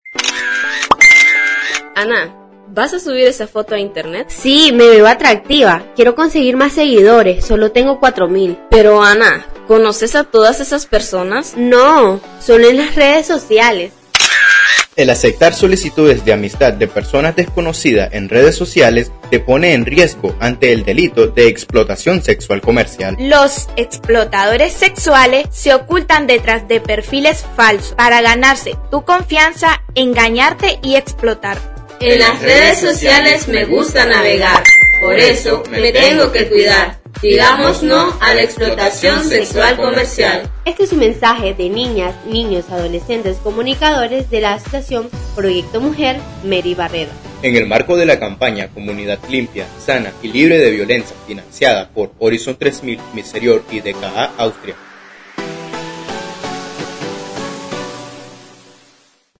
Les compartimos Campaña “Comunidad, Limpia, Sana y Libre de Violencia” ejecutada por niñas, niños y adolescentes comunicadores de la Asociación Mary Barreda en prevención de la violencia basada en género y la Explotación Sexual Comercial.